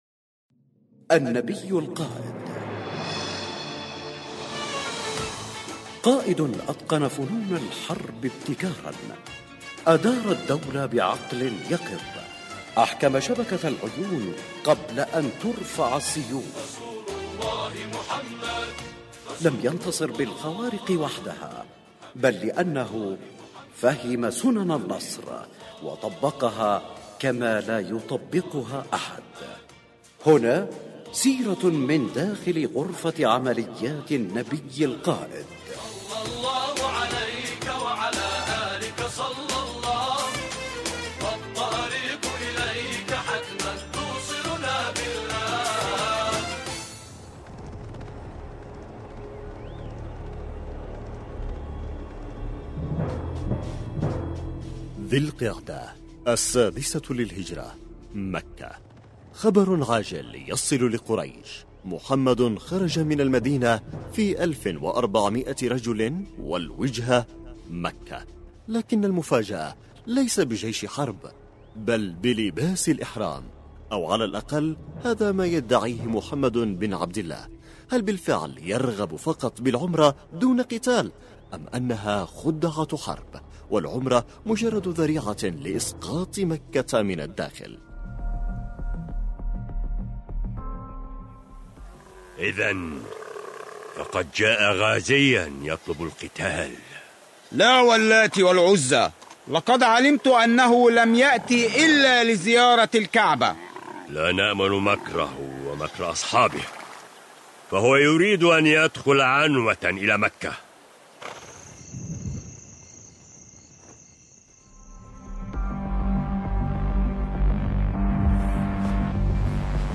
النبي القائد، برنامج إذاعي يقدم الجوانب العسكرية والأمنية في السيرة النبوية للنبي الاكرم صلى الله عليه واله مع الاعتماد بشكل كلي على ما ذكره السيد القائد يحفظه الله في محاضراته خلال رمضان وخلال المولد النبوي الشريف.